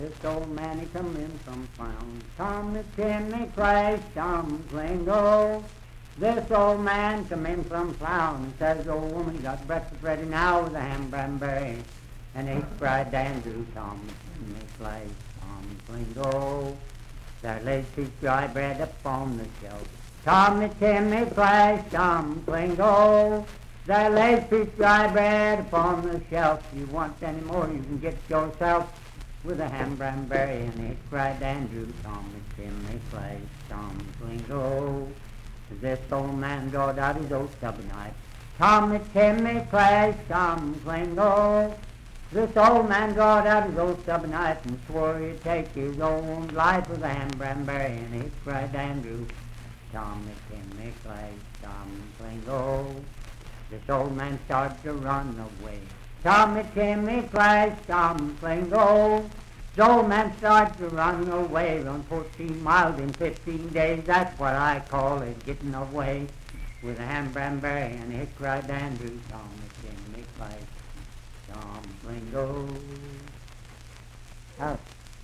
Unaccompanied vocal music and folktales
Verse-refrain 4(6w/R).
Voice (sung)